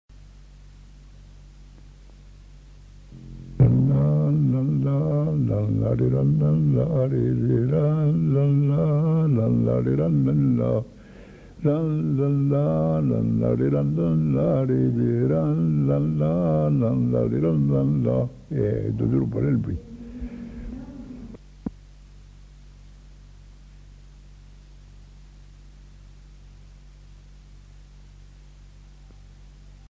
Bourrée
Aire culturelle : Haut-Agenais
Genre : chant
Effectif : 1
Type de voix : voix d'homme
Production du son : fredonné